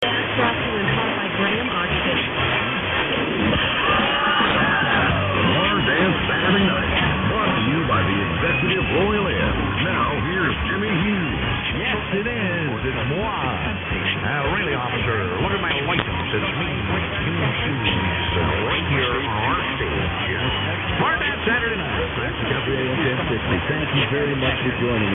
Mine are about 2 seconds apart beginning at :05 on my file.
CKMX is quite dominant in Seattle, but I can phase it down and hear a couple other stations that so far haven't IDed.
WBIX-1060-Possiblesweeptones.mp3